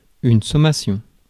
Ääntäminen
Ääntäminen France: IPA: [sɔ.ma.sjɔ̃] Haettu sana löytyi näillä lähdekielillä: ranska Käännös Konteksti Ääninäyte Substantiivit 1. adding US 2. summons 3. summation matematiikka 4. warning US Suku: f .